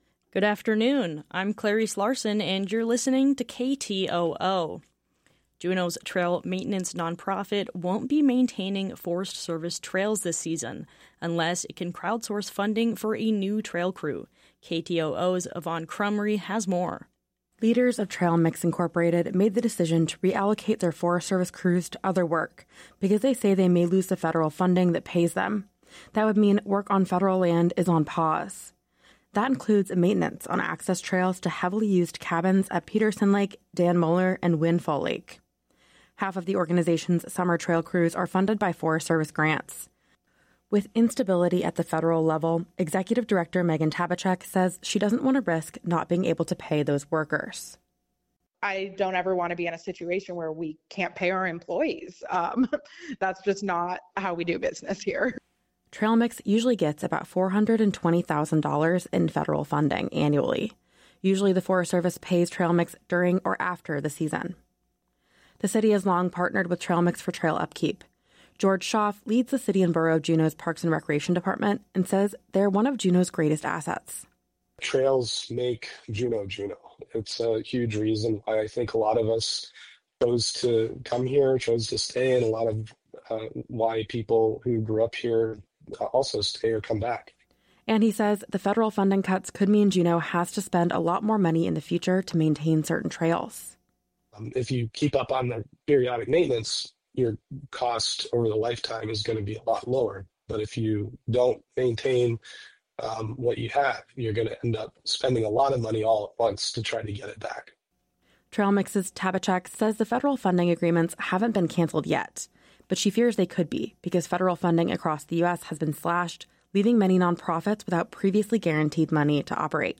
Newscast – Tuesday, March 4, 2025